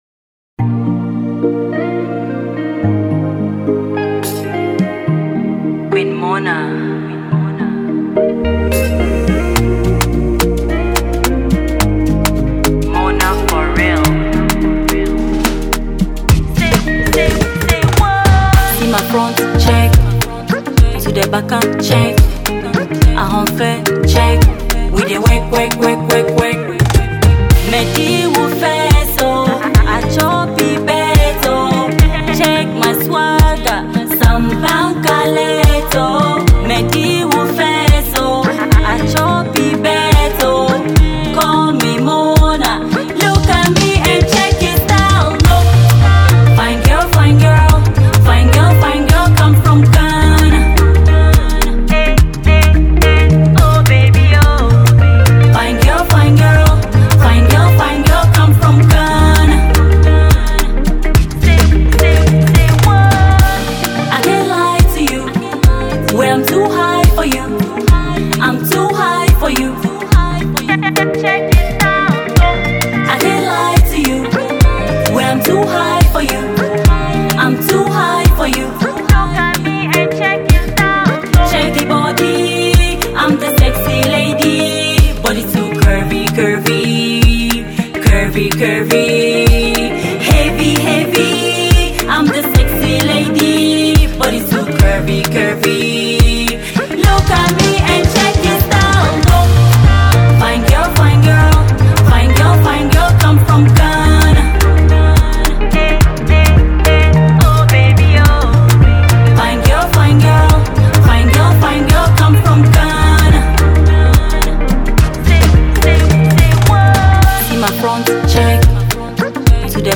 mid-tempo tune